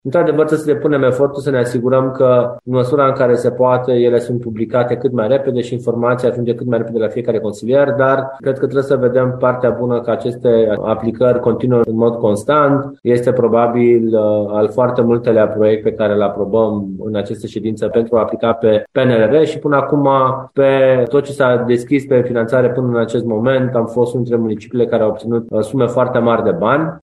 Viceprimarul Ruben Lațcău a explicat că unele ședințe sunt convocate de îndată pentru că, în cazul finanțărilor europene, termenele sunt foarte scurte: